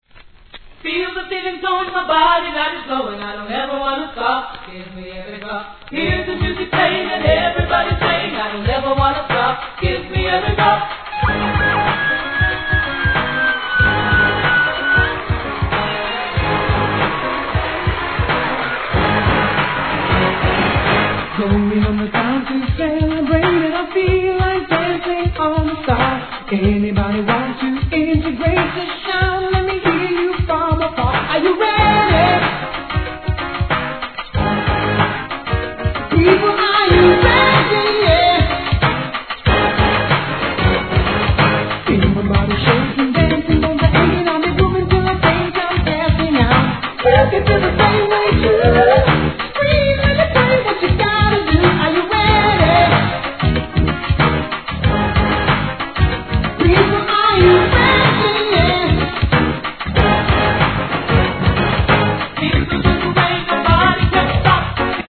REGGAE
後半で見せるRAPも最高!!